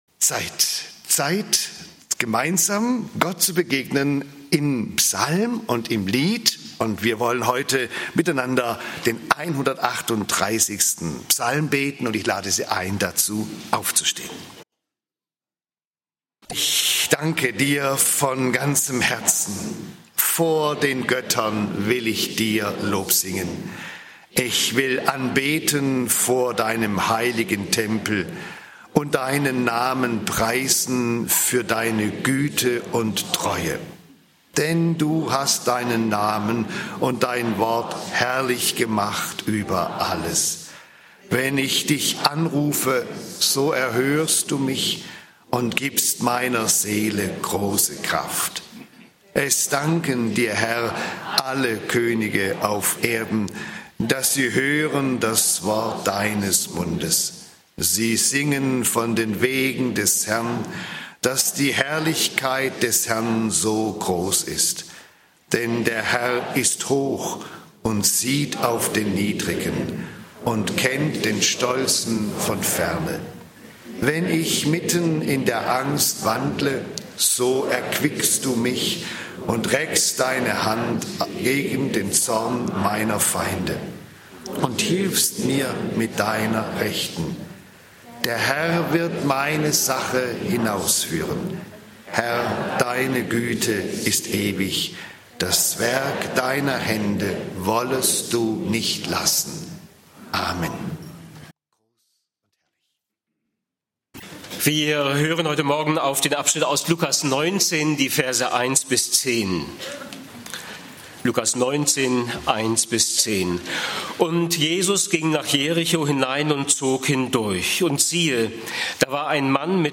Jesuswoche 2026 - "Ärgerlich": Jesusbegegnung unterm Maulbeerbaum (Lk. 19, 1-10) - Gottesdienst